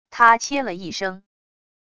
她切了一声wav音频